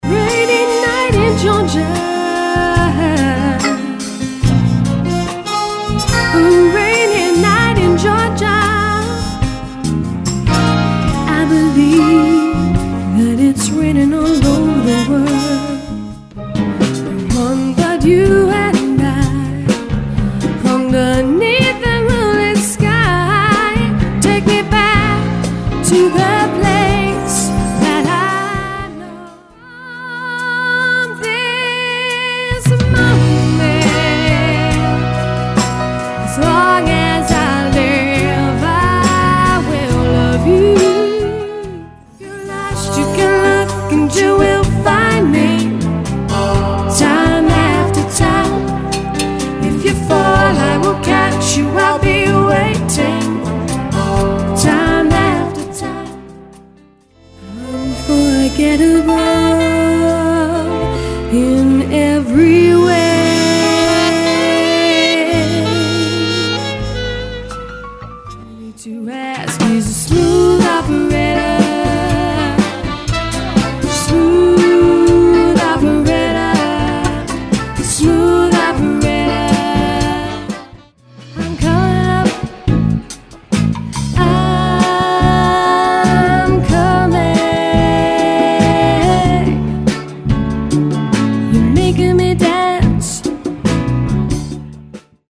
show band